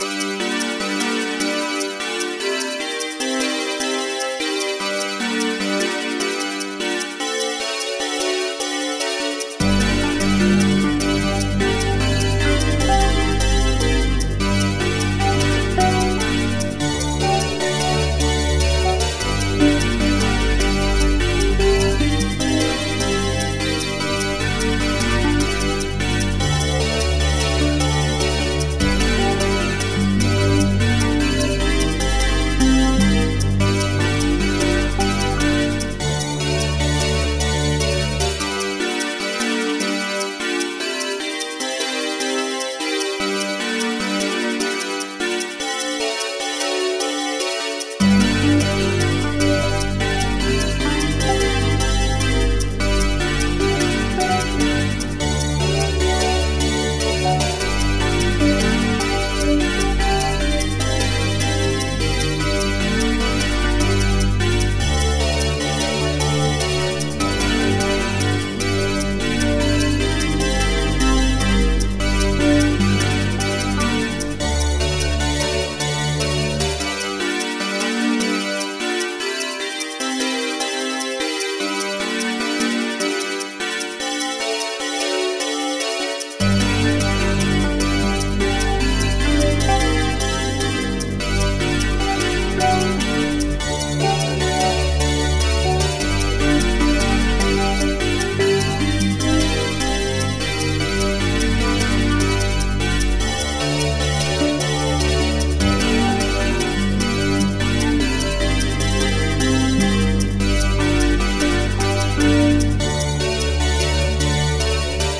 G Minor